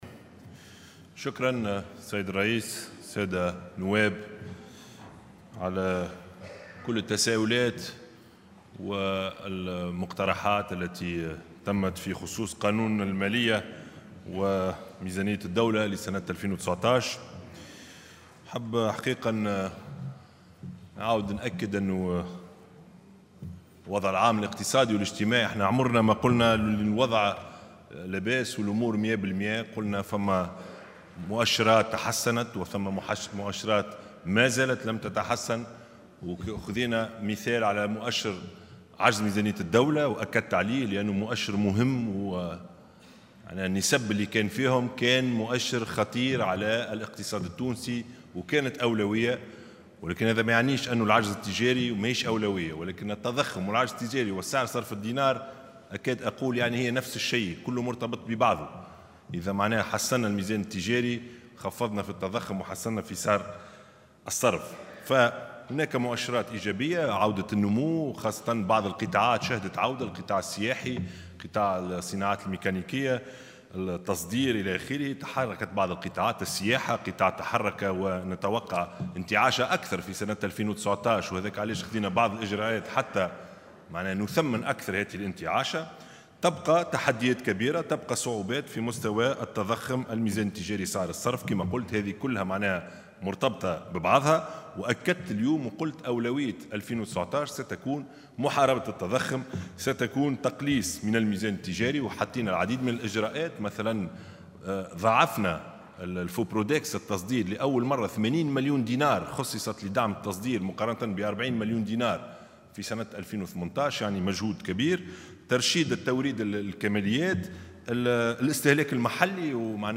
وأوضح في كلمة ألقاها للرد على تدخلات النواب ان التحديات الكبيرة تتمثل في التحكم في التضخم وسعر الصرف والعجز التجاري، مشيرا في هذا الصدد إلى تخصيص 80 مليون دينار لدعم التصدير بعنوان قانون المالية 2019 مقابل 40 مليون دينار في 2018.